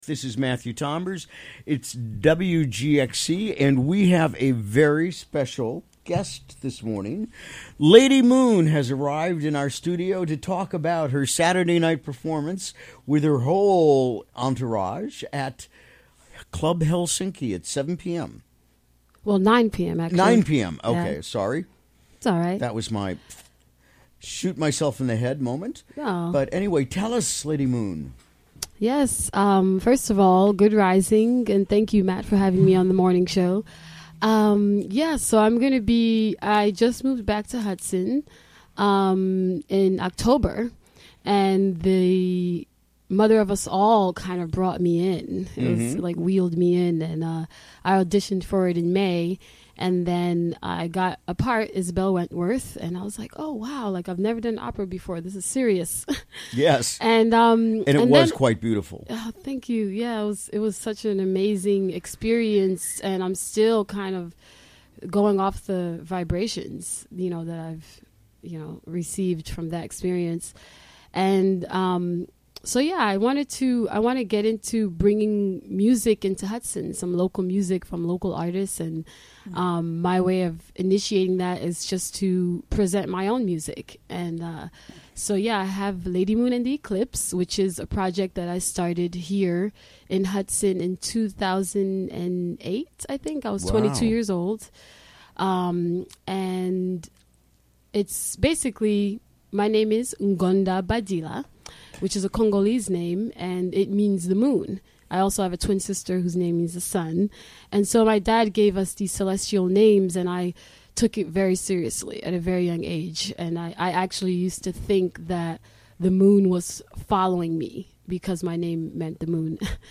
Recorded during the WGXC Morning Show of Wednesday, Dec. 6, 2017.